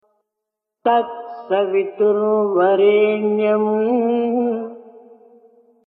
Gayatri recitado en forma lenta por Sai Baba, sloka por sloka:
Tat-savitur-varenyam-mas-lento.mp3